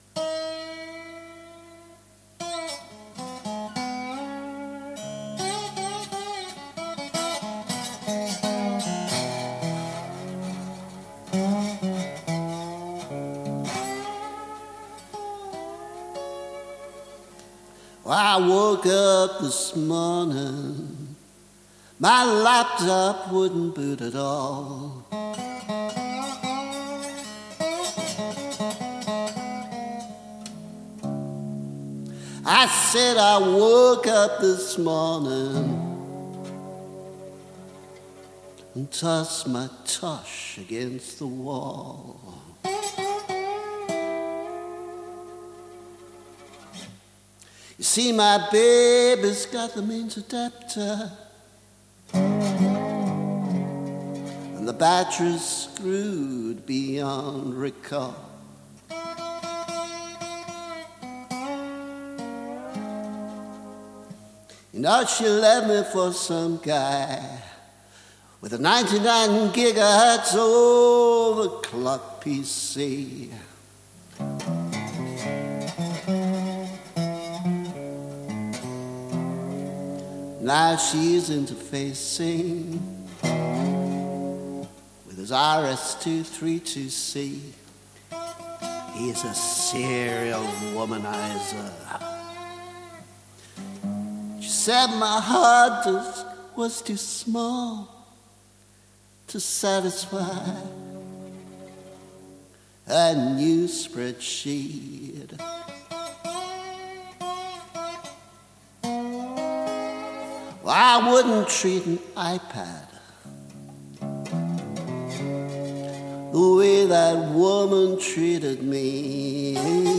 Remastered versions
I wouldn’t have mentioned any of this if it weren’t for a ludicrous conversation in a pub with someone who apparently thought I was setting PC for Dummies to music rather than writing a mildly amusing blues parody.